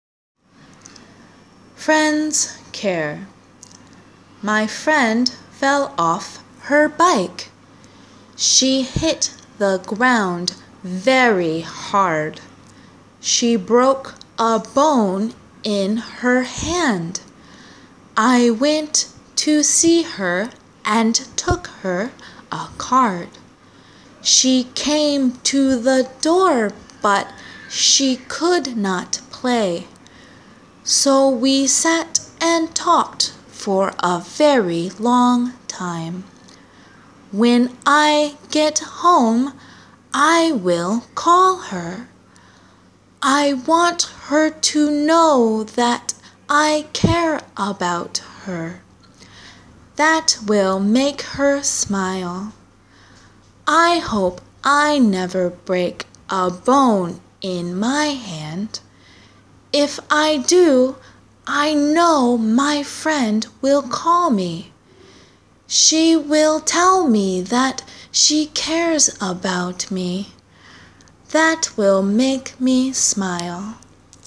■ゆっくり
Friends-Care-slow-ver..m4a